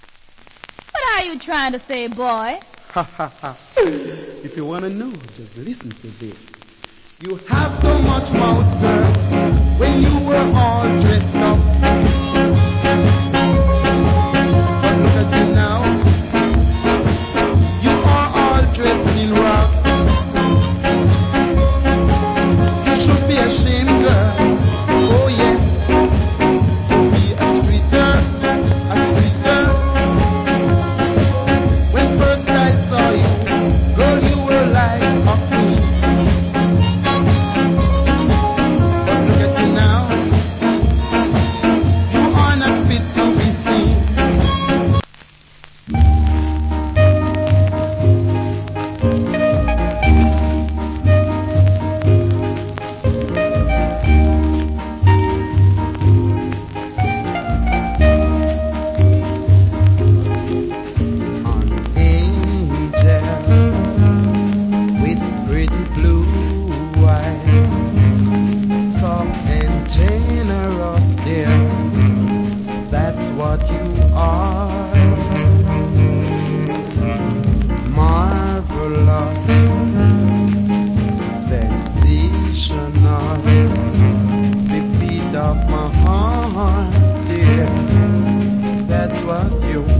Good Ska Vocal.